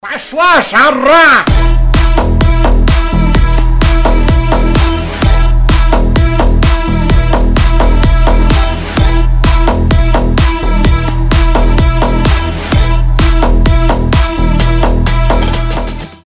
• Качество: 320, Stereo
громкие
мощные
веселые
Cover
Bass House
electro house
Mashup
кусок русского кавера на вьетнамский клубняк